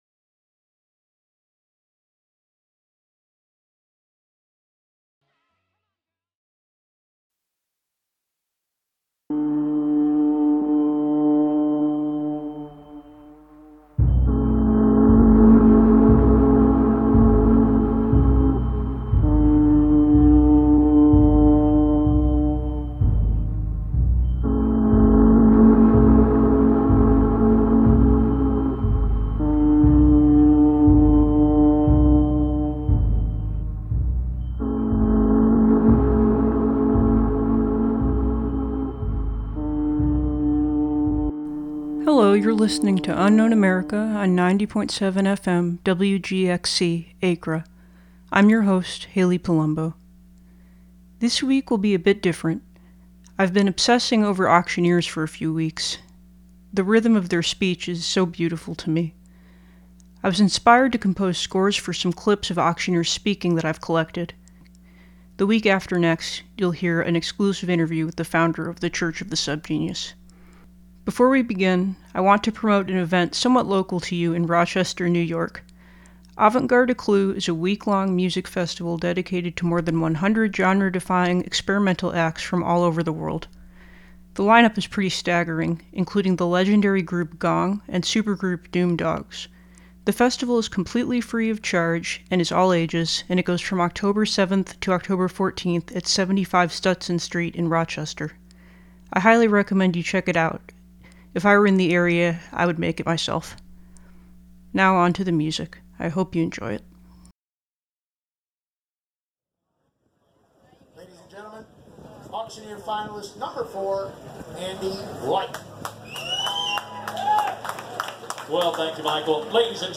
"Unknown America" is a show that dives into places, people, events, and other aspects of American history that often go overlooked. Through occasional interviews, on-site reporting, frantically obsessive research, and personal accounts, the listener will emerge out the other side just a bit wiser and more curious about the forgotten footnotes of history that make America fascinating, curious, and complicated.